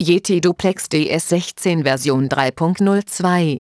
Sprachansagetext für neue Version V3.02